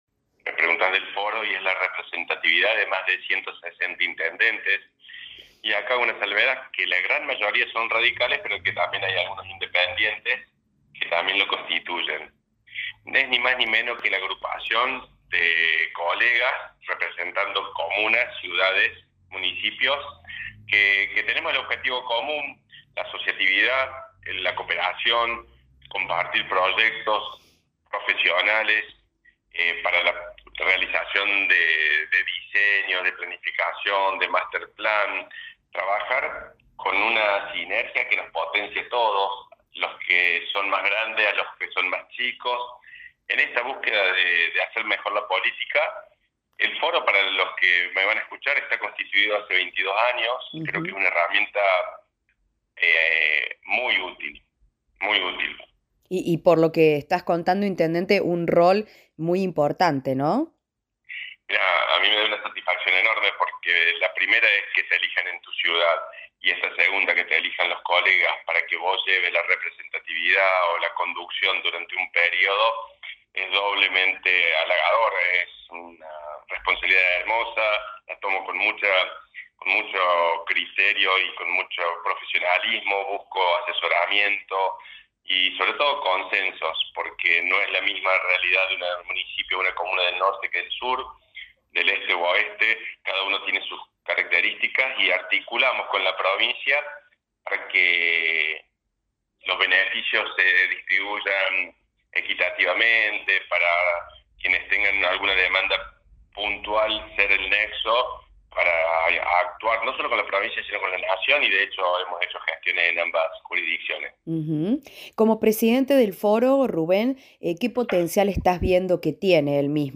Rubén Dagum, Intendente de la localidad de Almafuerte, es el presidente desde diciembre de 2023 del Foro de Intendente Radicales, un organismo creado hace 22 años y que a través del esfuerzo común y cooperación funciona como una fuerza que se retroalimenta para el bien de las más de 140 comunas y municipios que lo integran.